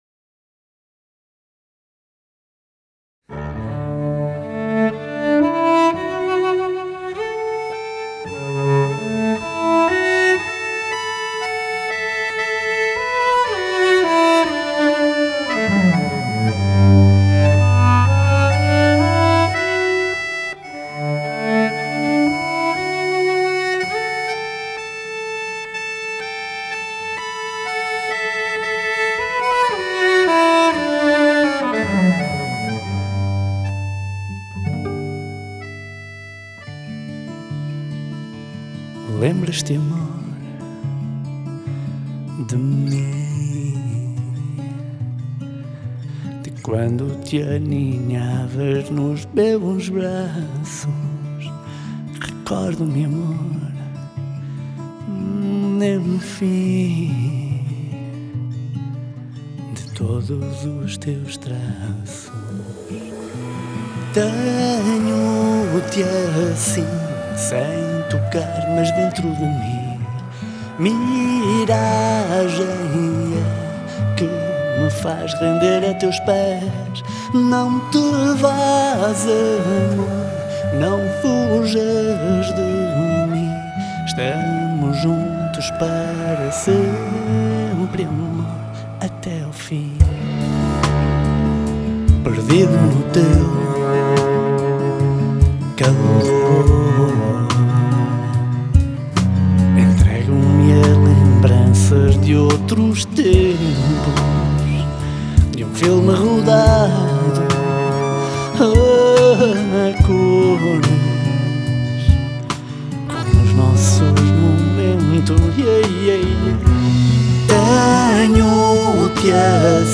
cello/vocals
percussion
acordeon
guitar/rap